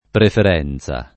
[ prefer $ n Z a ]